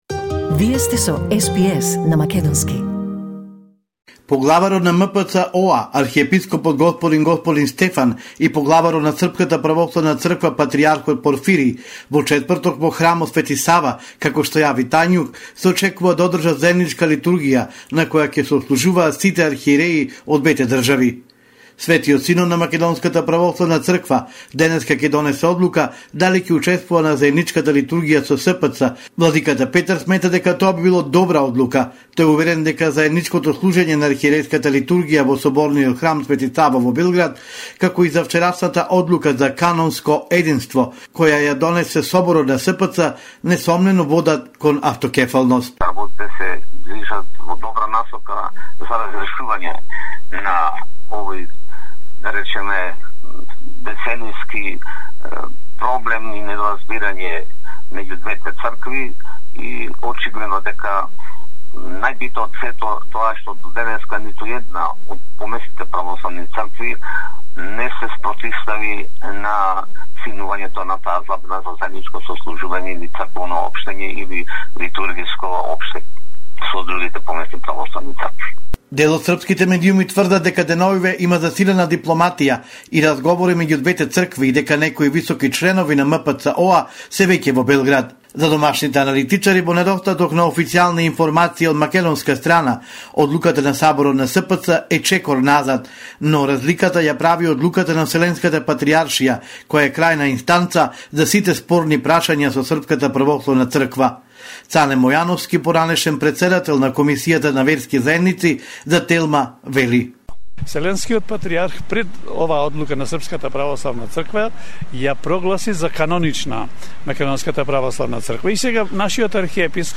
Homeland Report in Macedonian 18 may 2022